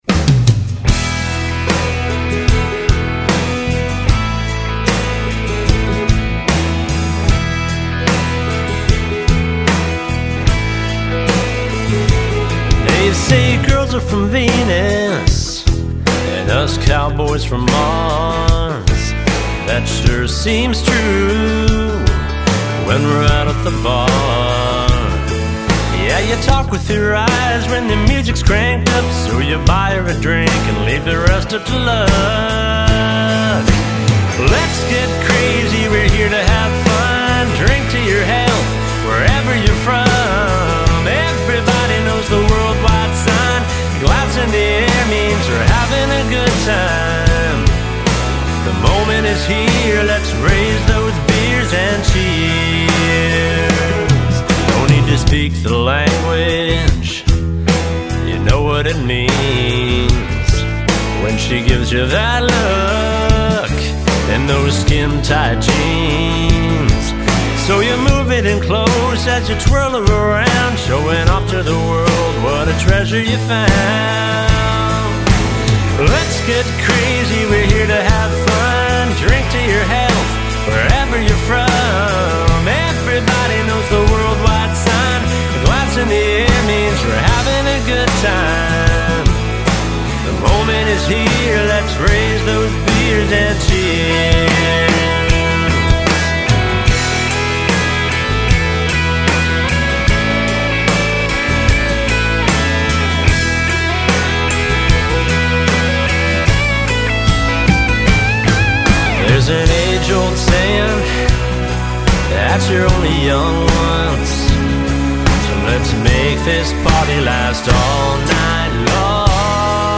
The Final Mix